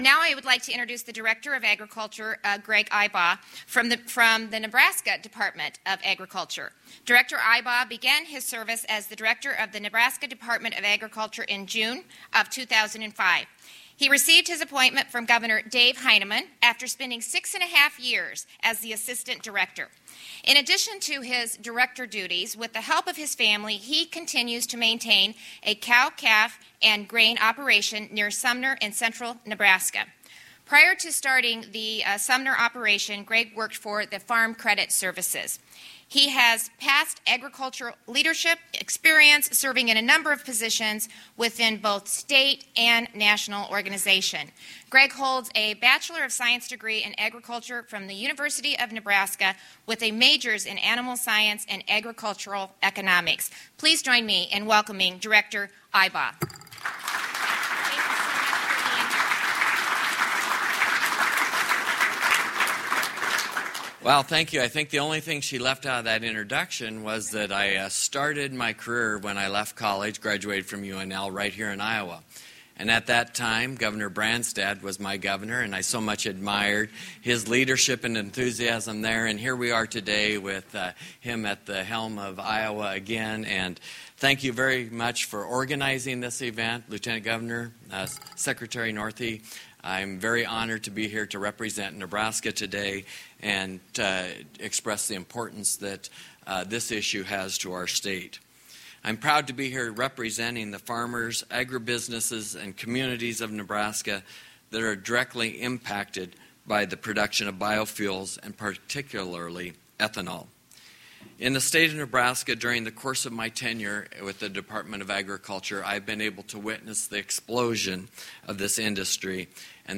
Audio from the hearing is being posted here.
Nebraska Department of Agriculture Director Greg Ibach –
Ibach comments